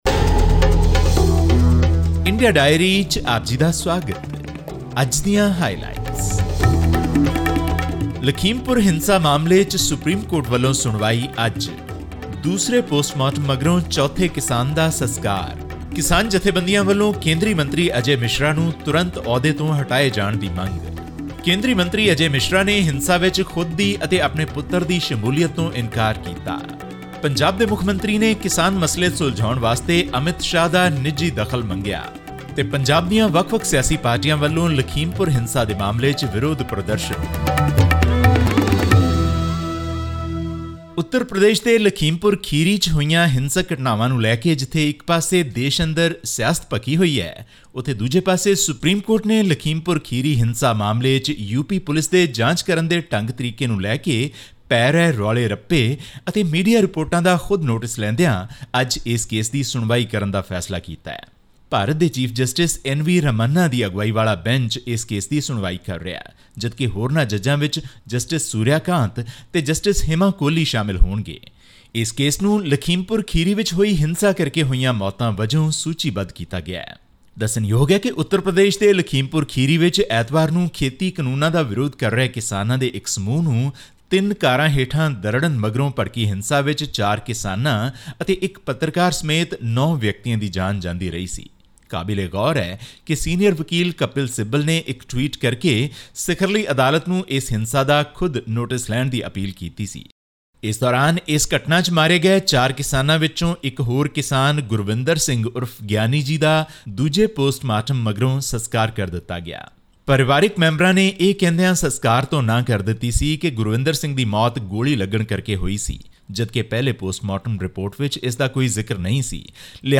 The top court on 7 October gave the Uttar Pradesh Government 24 hours to file a status report identifying the accused in the First Information Report (FIR) registered on the Lakhimpur Kheri incident. This and more in our weekly news update from India.